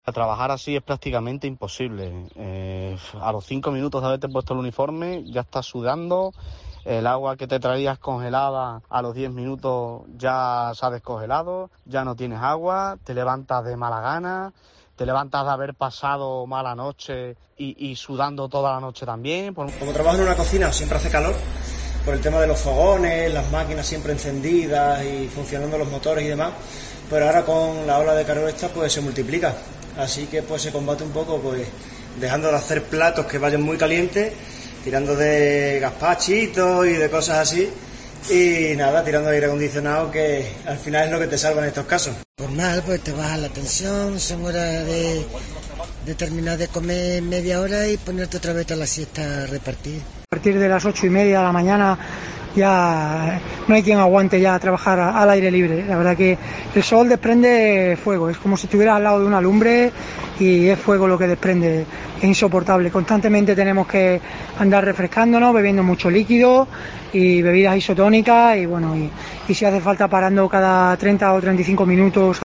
Hablan extremeños que trabajan en la calle y en cocinas